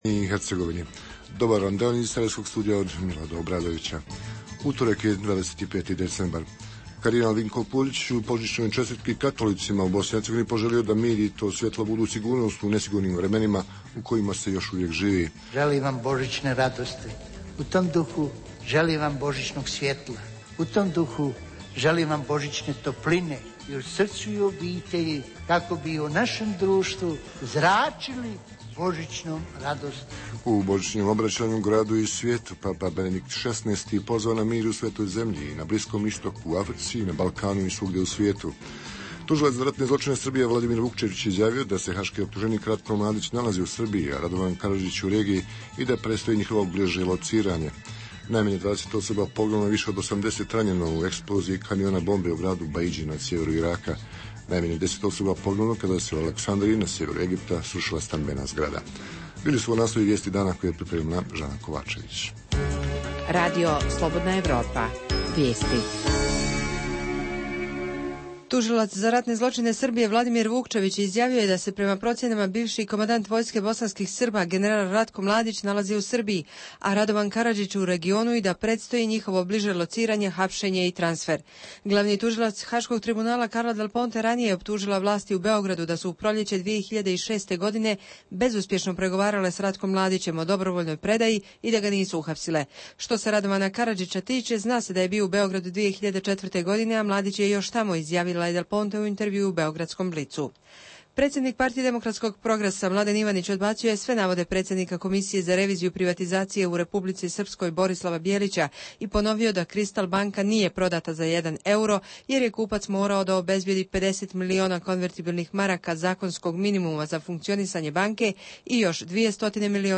U emisiji, između ostalog, možete čuti: obraćanje vrhbosanskog nadbiskupa kardinala Vinka Puljića na božićnoj misi, o povratku Hrvata u Republiku Srpsku, podsjećanje na sve naše visoke predstavnike, reportažu iz narodne kuhinje, o tome koliko i koji su bh. političari bili (ne)aktivni ove godine te kuda otići kada je vazduh u našim gradovima zagađen.